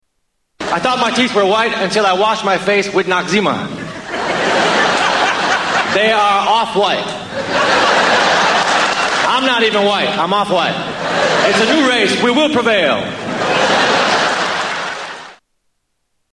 Tags: Comedians Mitch Hedberg Sounds Mitchell Lee Hedberg Mitch Hedberg Clips Stand-up Comedian